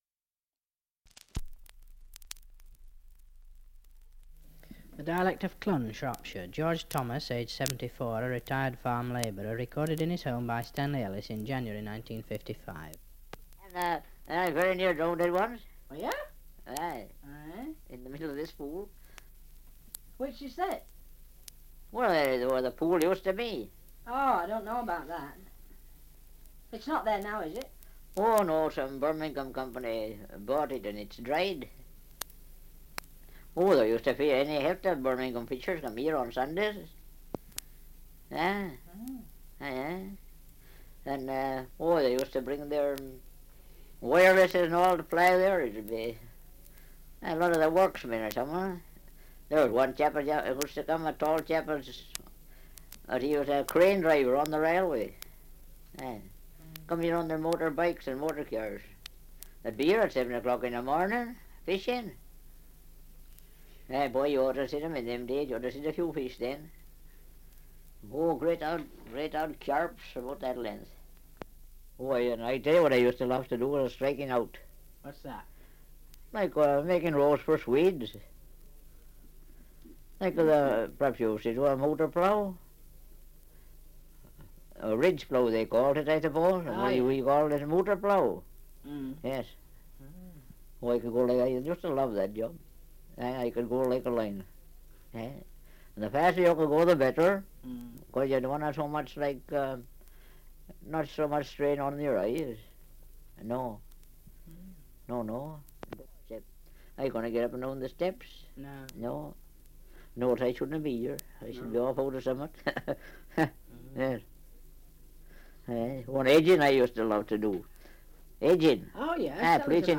Survey of English Dialects recording in Clun, Shropshire
78 r.p.m., cellulose nitrate on aluminium